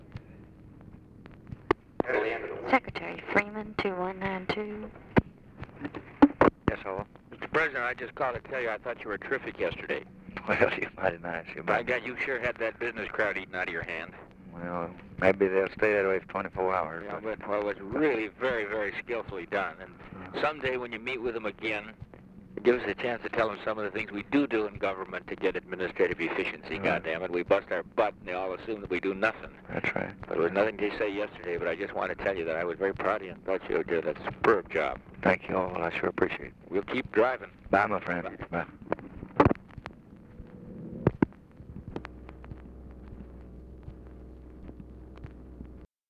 Conversation with ORVILLE FREEMAN, December 5, 1963
Secret White House Tapes